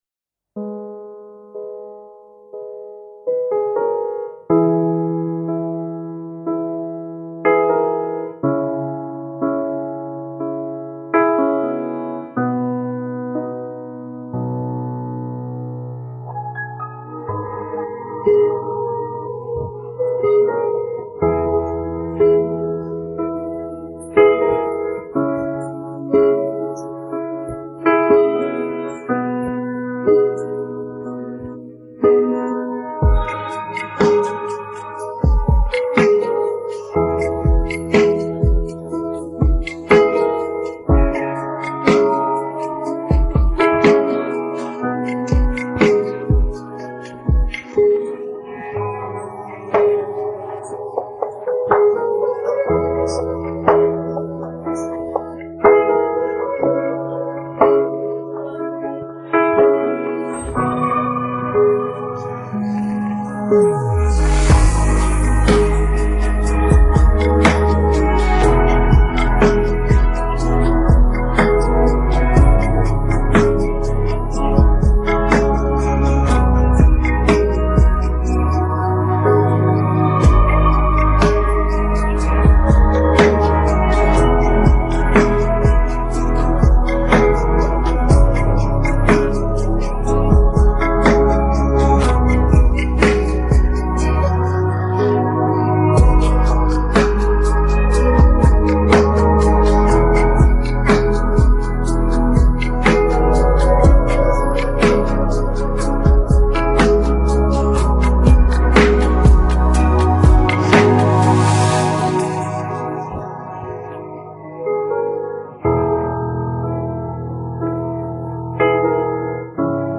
پخش نسخه بی‌کلام